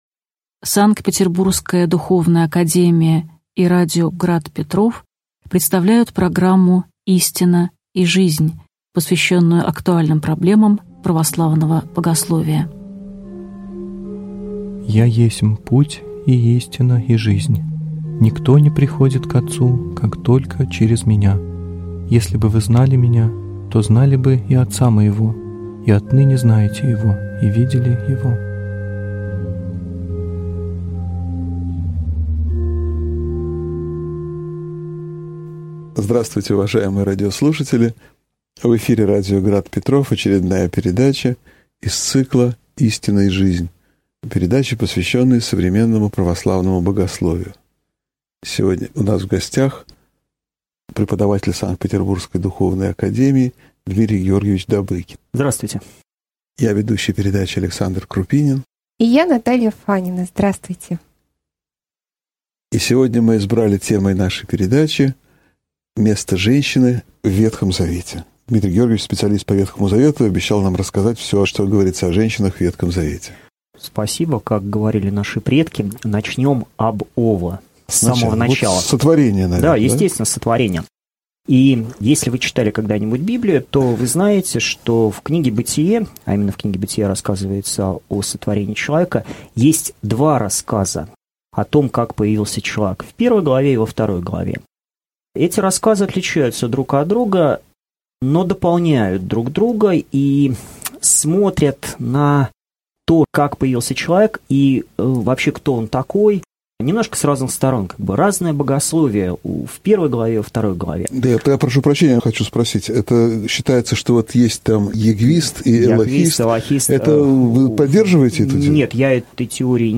Аудиокнига Мужчина и женщина в Священном Писании (часть 1) | Библиотека аудиокниг